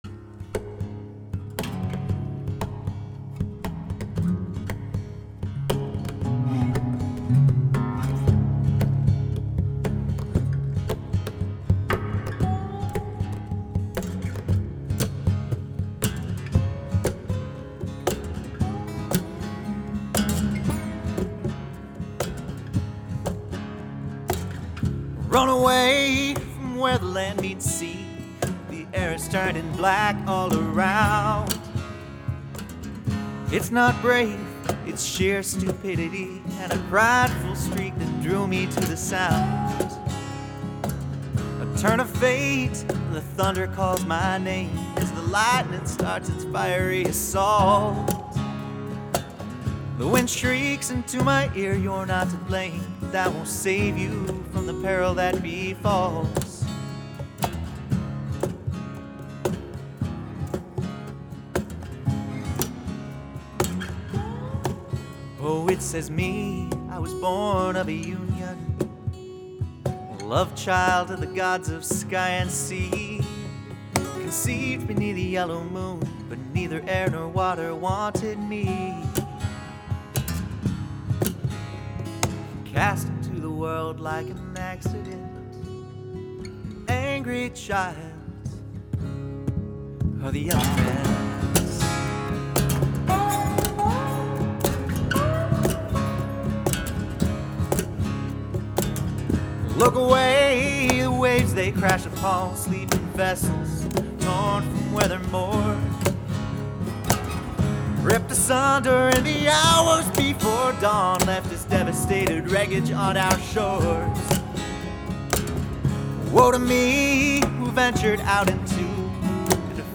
Hi all, This is an original that I played, recorded, and mixed. The musicianship is imperfect, to be sure, but it'll do.
It's touch messy; that's part of what I'm going for.
What's going on in this track: - Double-tracked acoustic in open tuning- panned right and left. Each is a blend of a condenser mic on the 12th fret and a DI out from the guitar.
- Steel guitar is an el-cheapo resonator that I limped along with in open tuning.
- Percussion is weird. The beginning is me banging on the guitar body. After a couple of bars, I move into a cajon, mic'd with a condenser in front and a dynamic aimed at the rear sound hole.
I had an alternate pair of tracks from banging on the guitar, and I kept the DI component, which added a cool (I though) throbbing low end with the open tuning. Another track of nylon brushes on the cajon, recorded with a condenser, and run through a HP filter. - Background vox is just some harmonized humming, rolled off at the higher frequencies and run through an aggressive compressor to equalize the different tracks. - Vox, guitar, and percussion were bussed to a shared reverb.